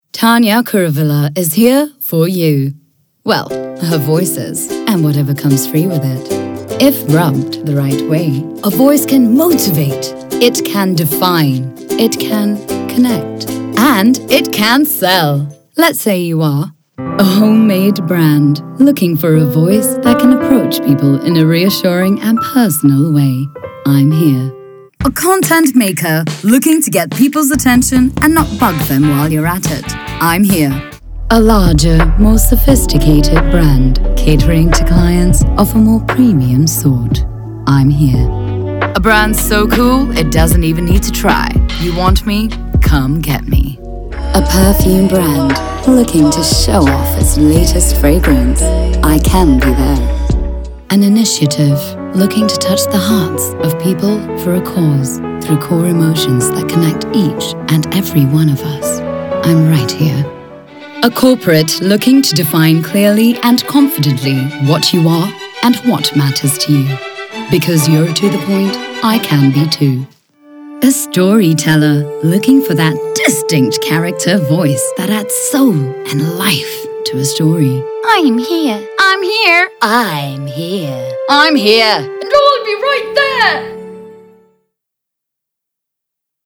A demo with many voices